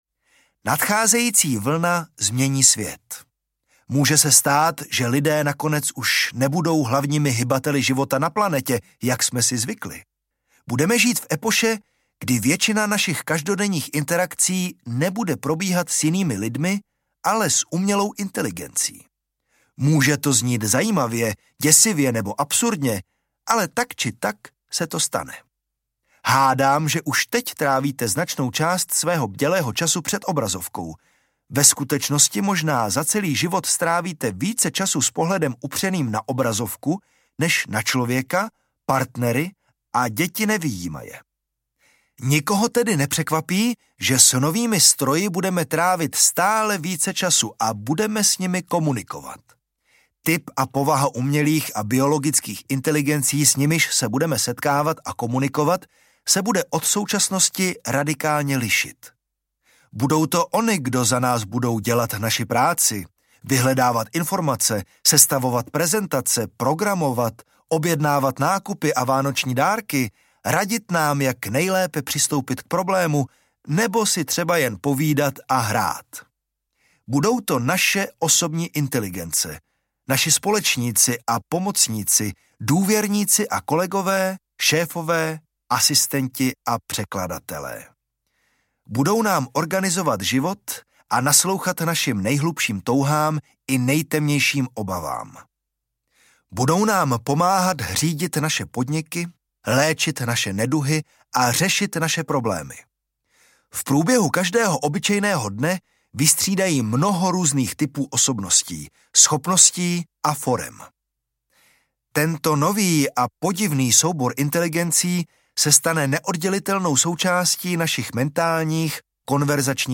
Nezadržitelná vlna audiokniha
Ukázka z knihy